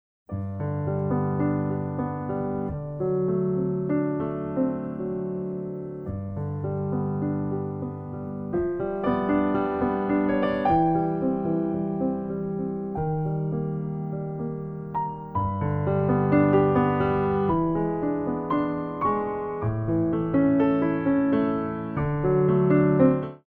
Adagio